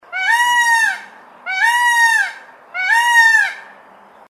Звуки павлина
Павлин – красивая птица, но голос у него не самый приятный.